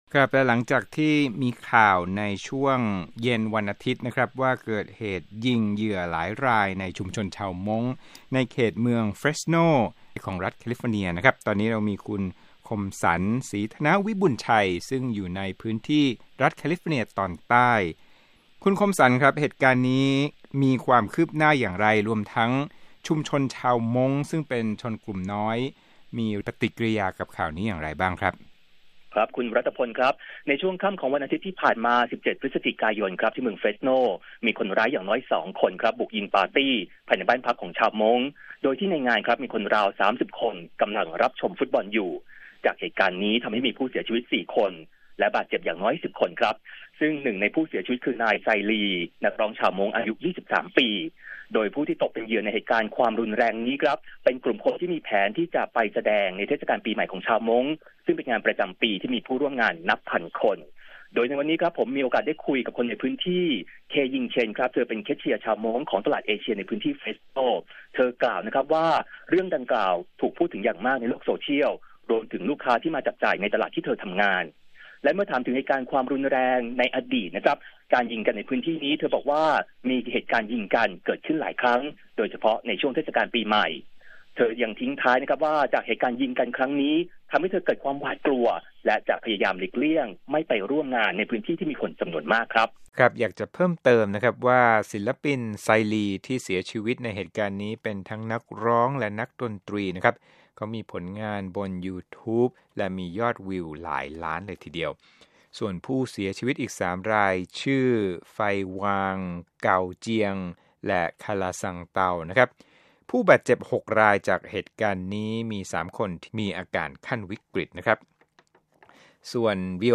Hmong Shooting Phone Report